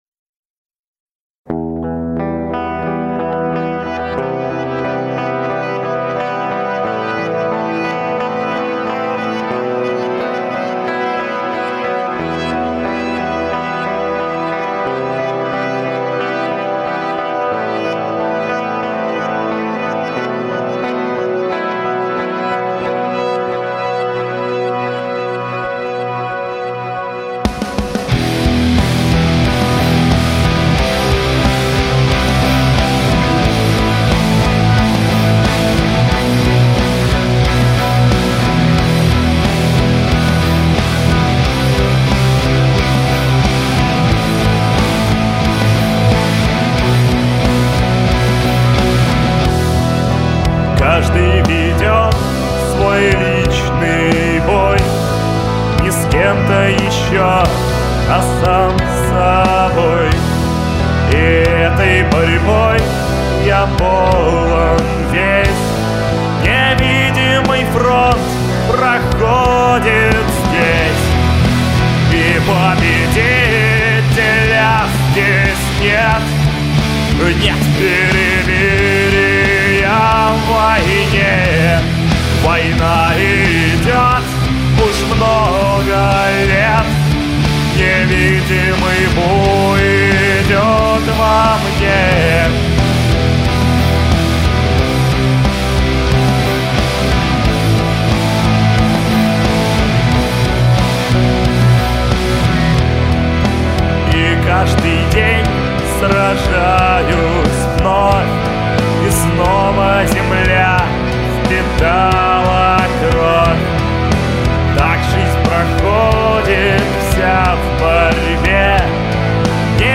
Сведение рока